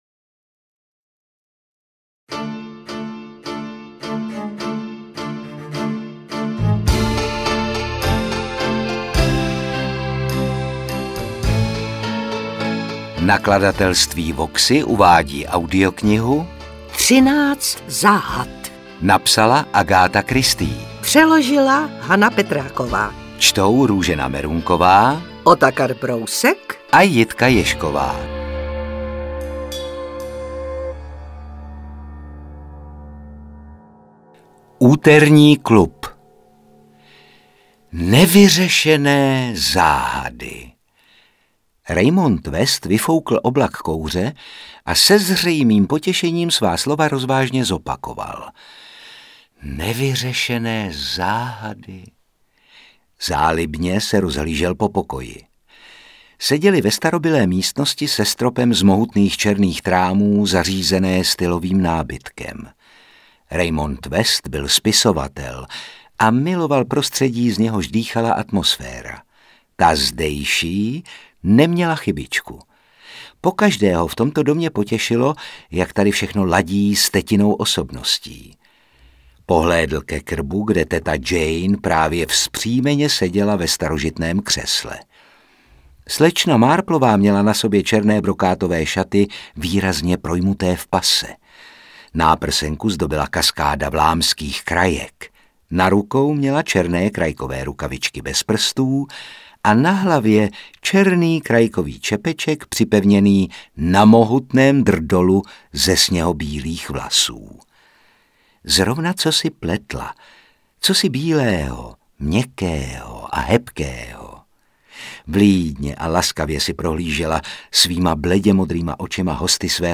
Interpret:  Růžena Merunková